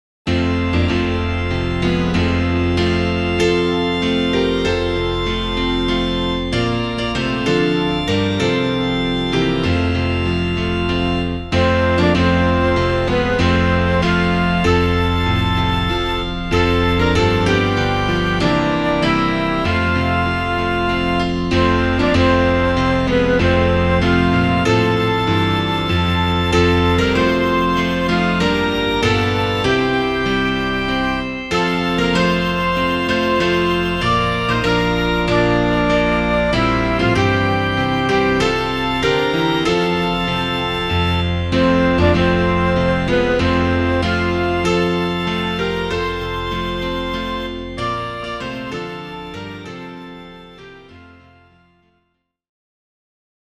Meter: Irregular
Key: F Major